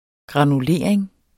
Udtale [ gʁɑnuˈleˀɐ̯eŋ ]